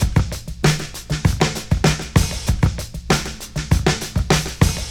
• 98 Bpm HQ Breakbeat Sample E Key.wav
Free drum beat - kick tuned to the E note. Loudest frequency: 1652Hz
98-bpm-hq-breakbeat-sample-e-key-16j.wav